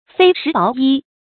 菲食薄衣 注音： ㄈㄟˇ ㄕㄧˊ ㄅㄛˊ ㄧ 讀音讀法： 意思解釋： 菲：微薄。